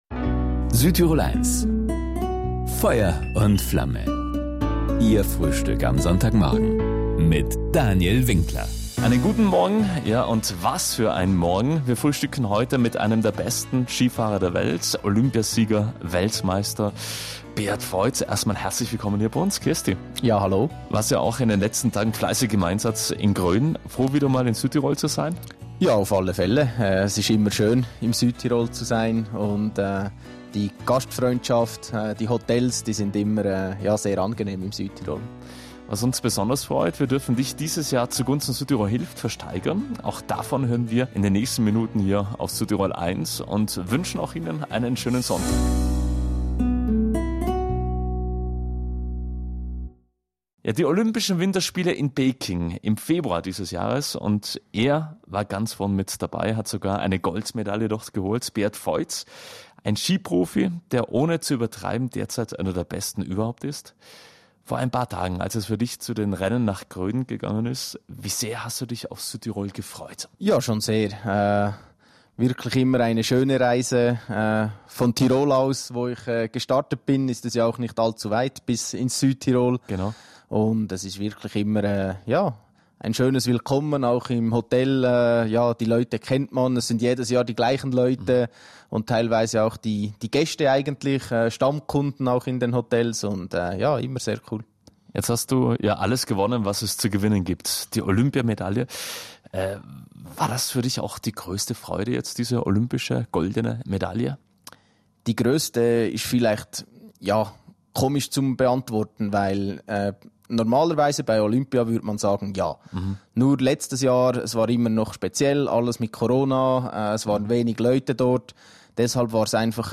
Ausführlich davon erzählt Beat Feuz diesmal im Sonntagsfrühstück auf Südtirol 1, aber vor allem auch von seinem Angebot bei der Promiversteigerung zu Gunsten „Südtirol hilft“.